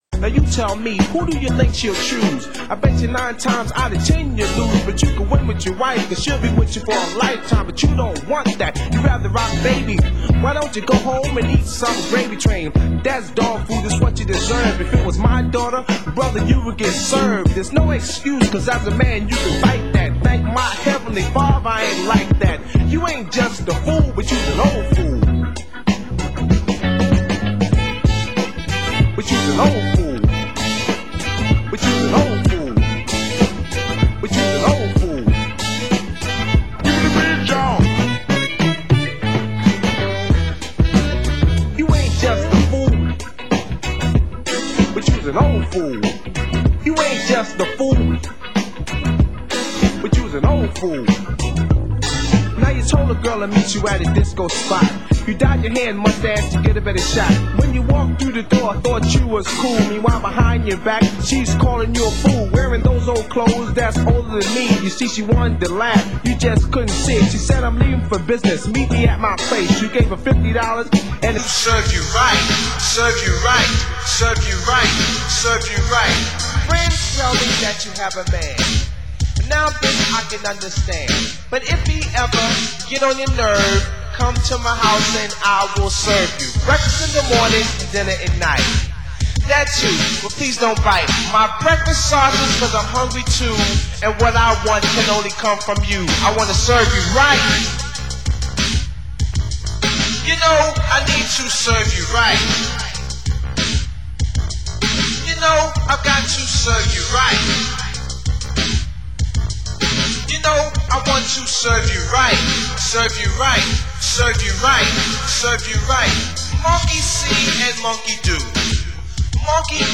Genre: Old Skool Electro